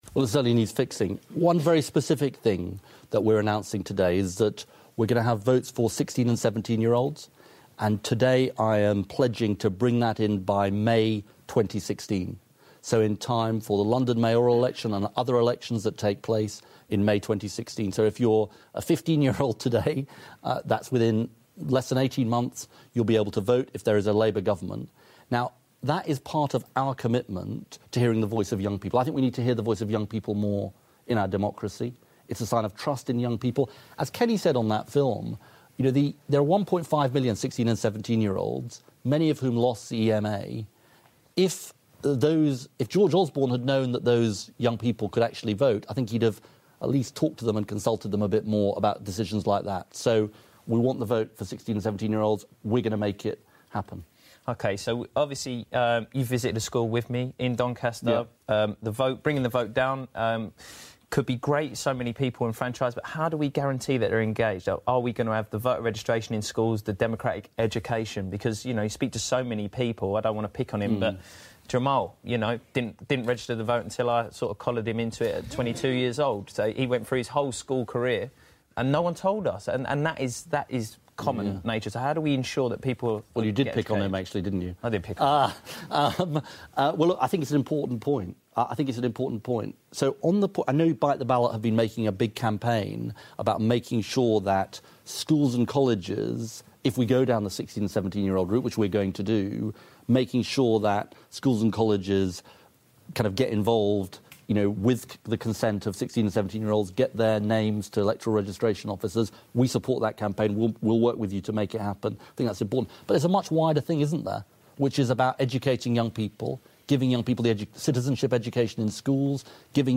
From Bite The Ballot's Leaders Live, 8 December 2014